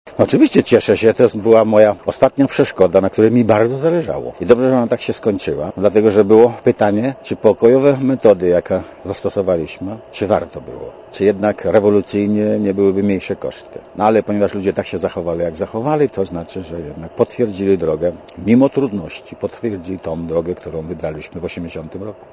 Cieszę się, dobrze się skończyło – powiedział Radiu Zet Lech Wałęsa.
Komentarz audio